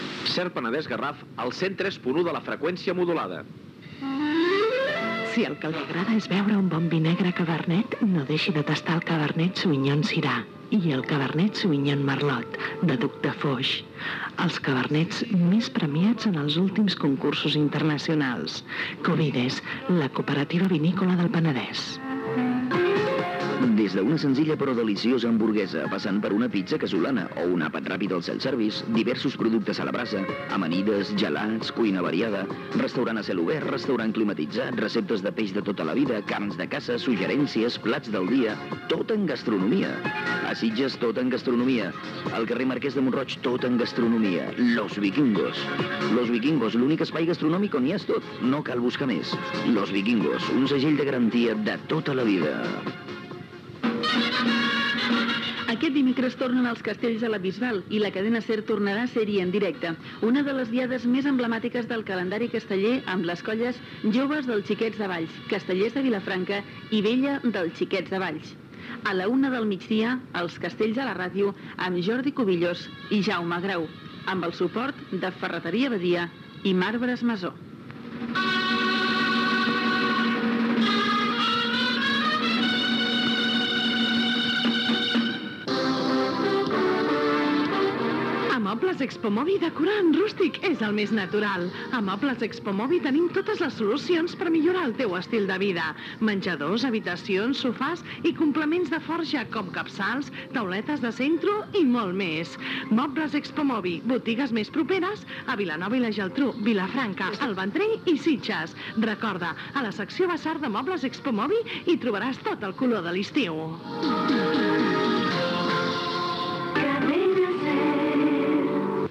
Indicatiu de l'emissora, publicitat, promoció de "Els castells a la ràdio", indicatiu de la cadena
FM